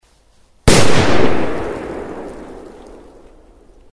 Звуки петард